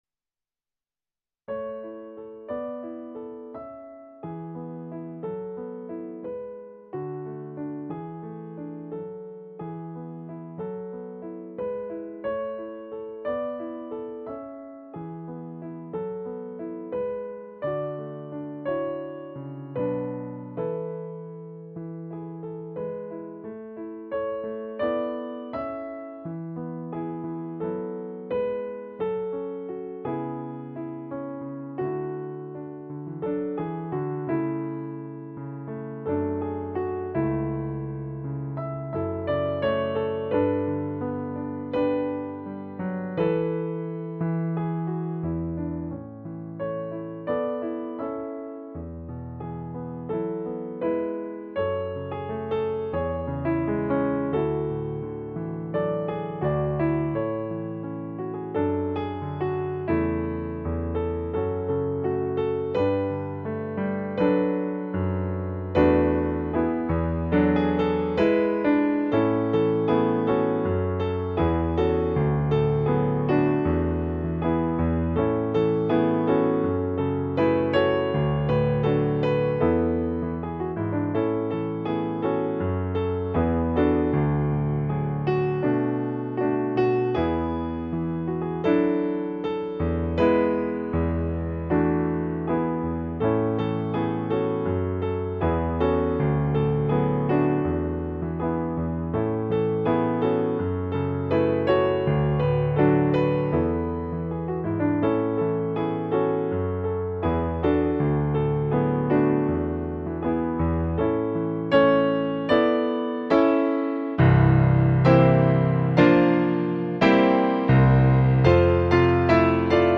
piano arrangement